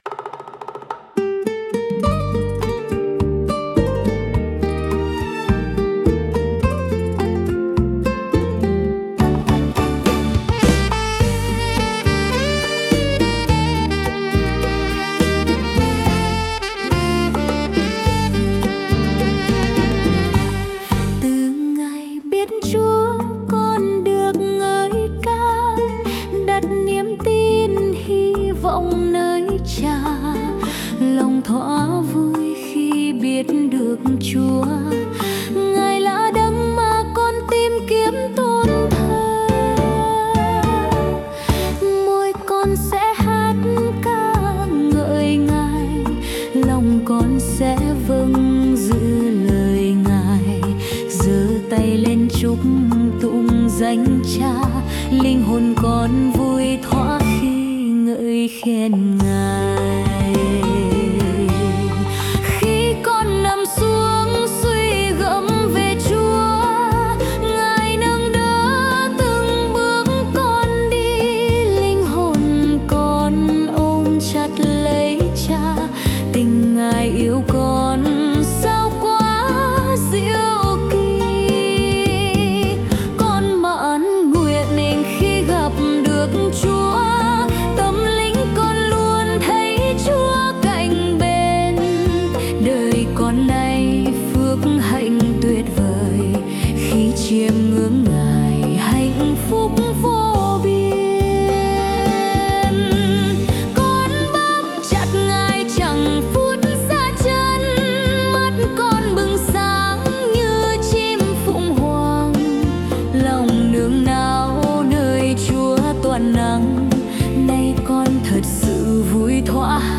Nhạc AI